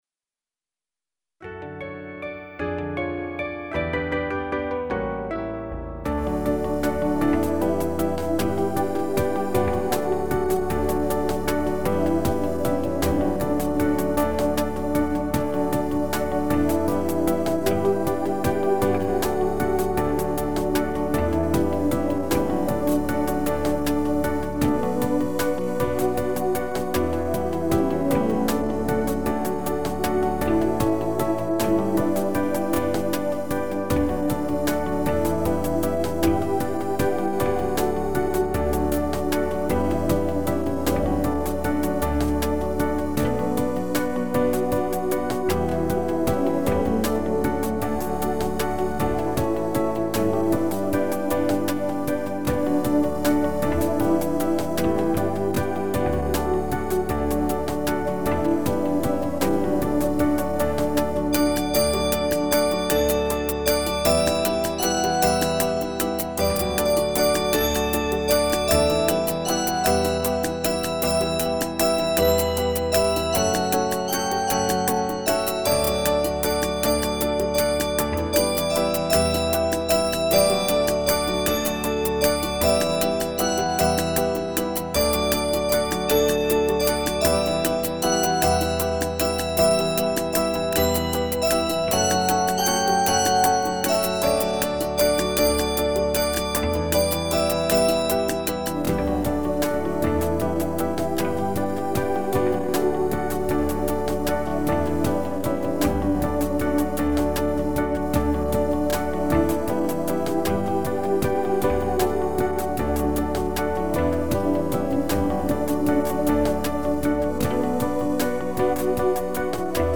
Фонограмма: